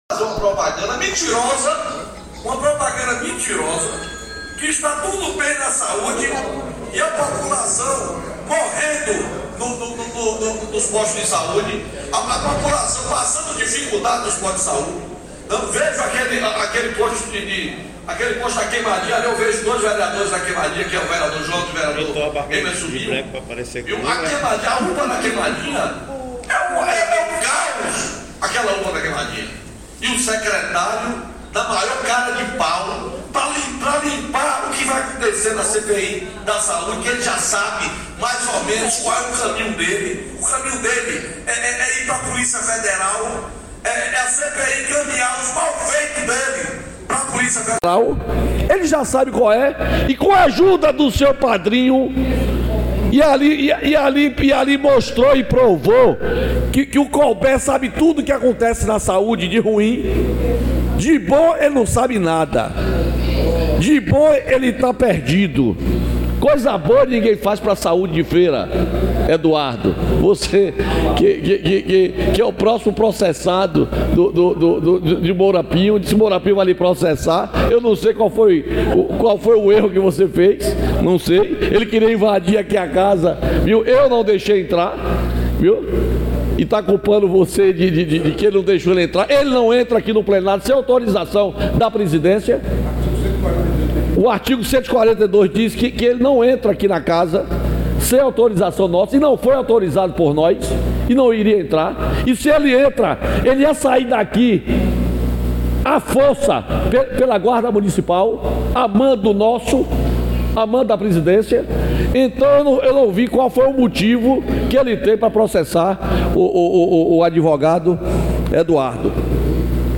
Durante discurso nesta terça-feira (10) no plenário da Câmara Municipal de Feira de Santana, o vereador e presidente da Casa Legislativa, teceu duras críticas ao secretário de saúde Dr. Marcelo Brito e ao prefeito Colbert Martins MDB, por propaganda na TV Subaé sobre a saúde.